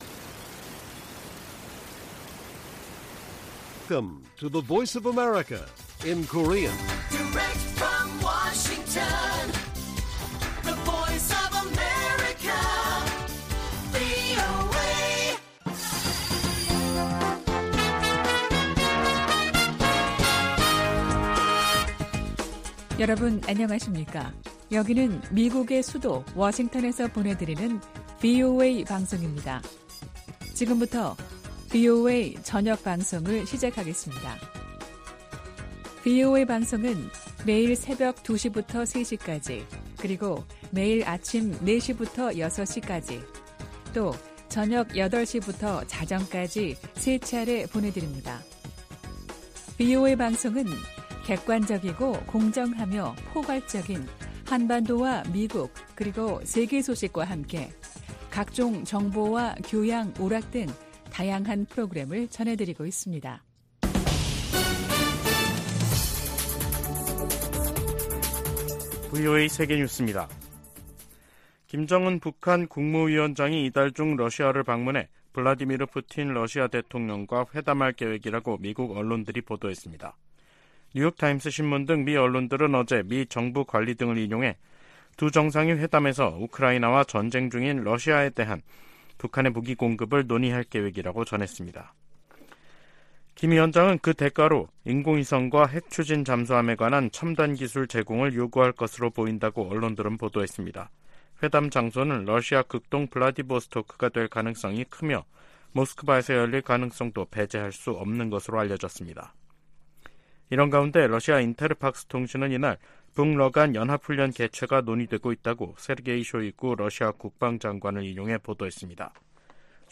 VOA 한국어 간판 뉴스 프로그램 '뉴스 투데이', 2023년 9월 5일 1부 방송입니다. 백악관은 북한 김정은 위원장의 러시아 방문에 관한 정보를 입수했다고 밝혔습니다. 미 국무부는 북한과 러시아의 연합 군사훈련 논의 가능성을 비판했습니다.